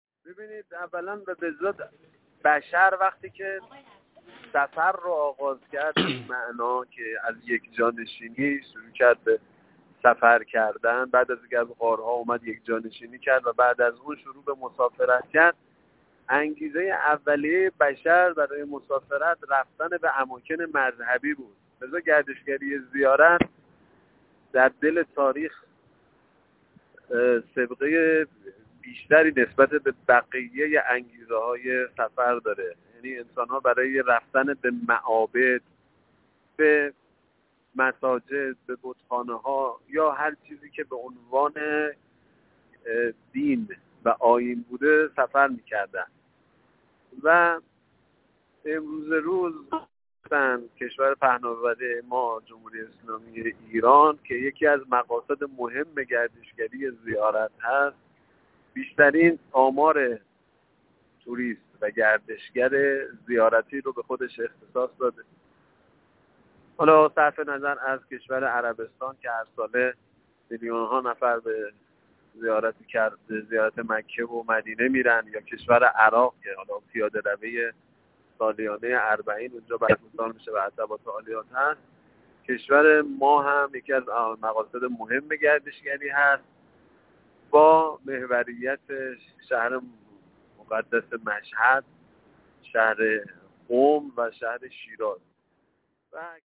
یک فعال گردشگری مطرح کرد: